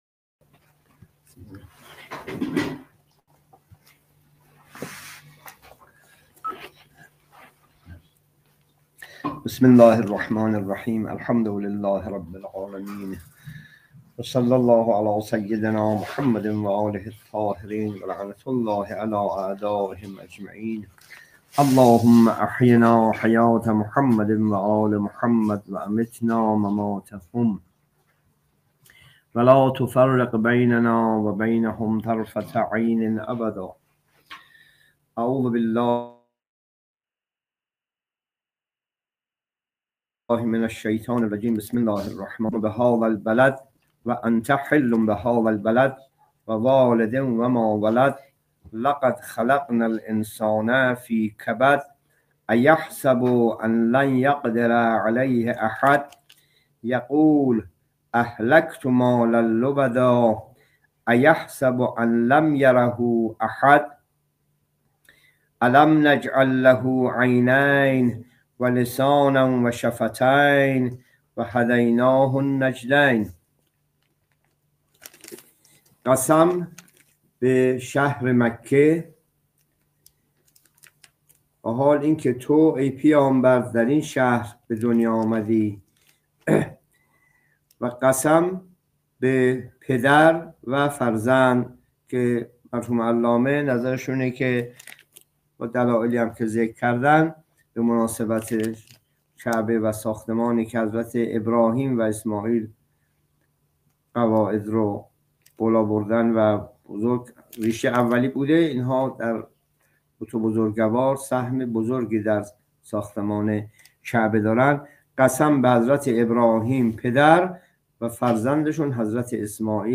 جلسه تفسیر قرآن (26) سوره بلد